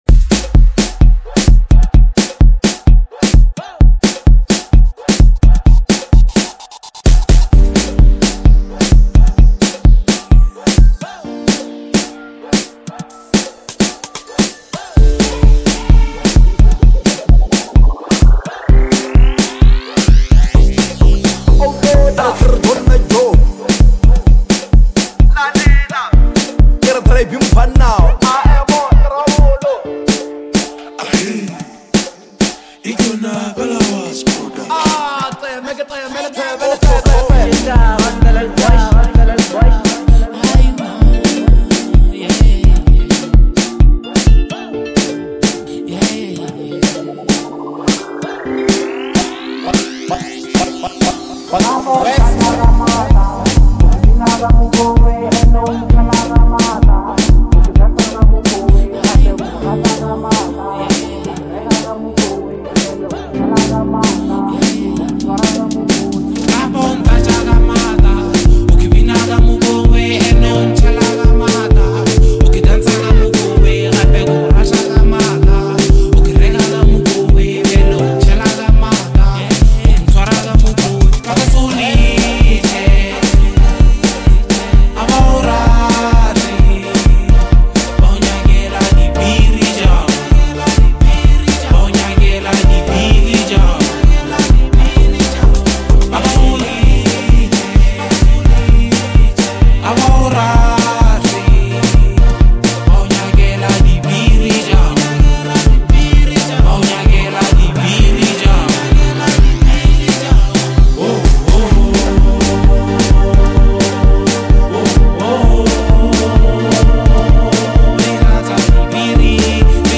Lekompo
a high-energy lekompo anthem